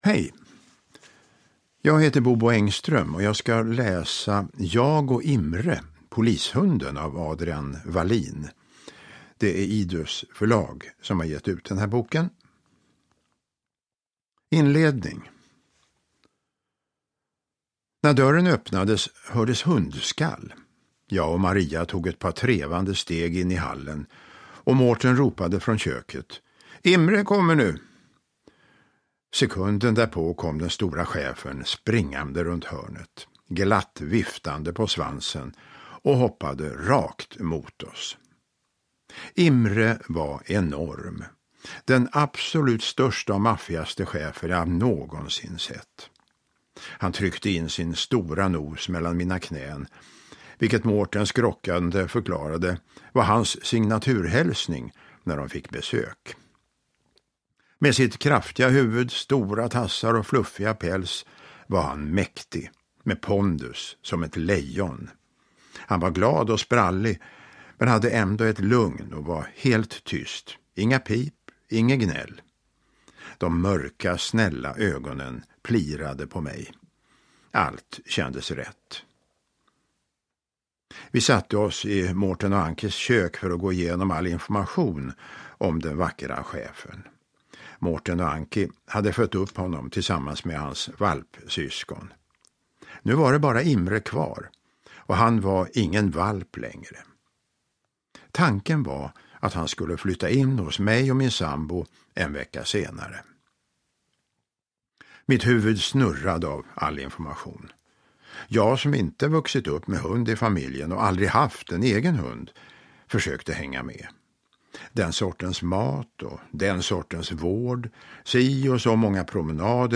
Jag och Imre, polishunden (ljudbok) av Adrian Wallin